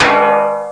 00034_Sound_tang.mp3